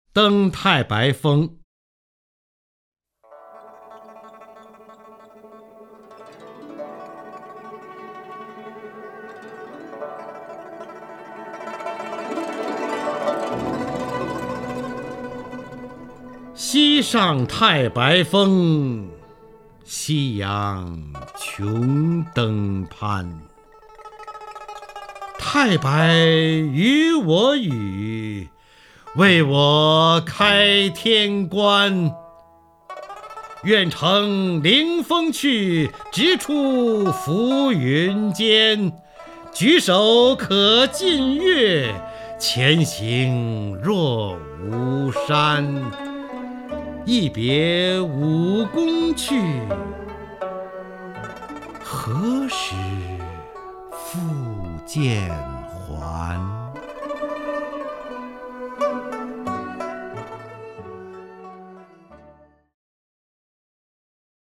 方明朗诵：《登太白峰》(（唐）李白)
名家朗诵欣赏 方明 目录
DengTaiBaiFeng_LiBai(FangMing).mp3